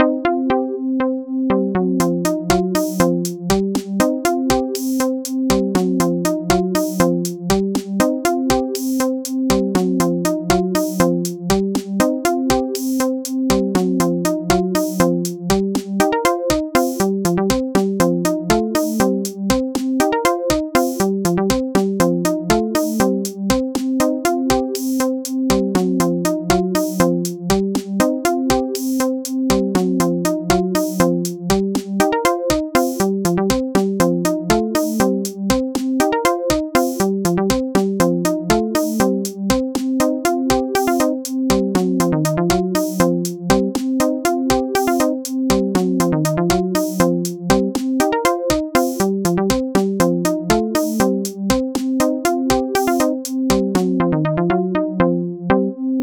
What a groove!